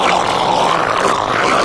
fast_zombie
gurgle_loop1.ogg